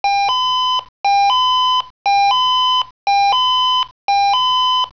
Alarmierung
2000- 2001 vom Typ Swissphone Quattro 96